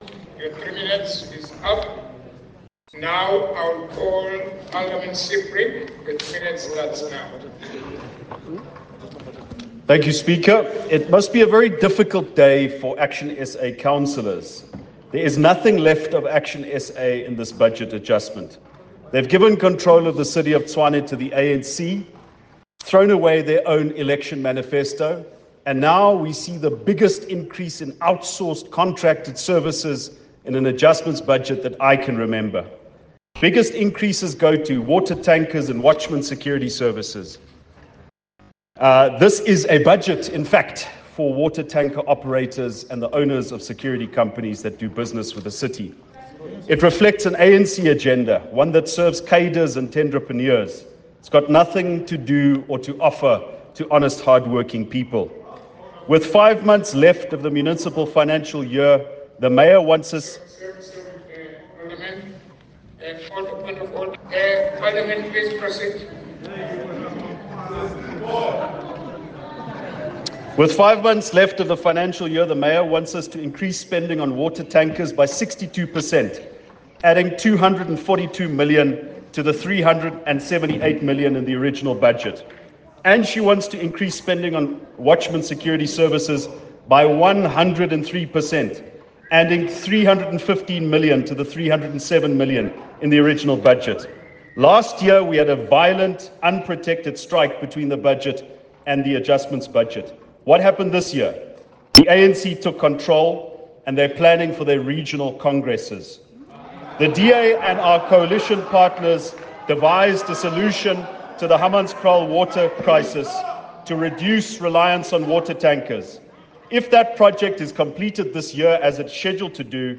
This speech was delivered in the Tshwane Council Chamber by Ald Cilliers Brink, in reply to the proposed adjustment budget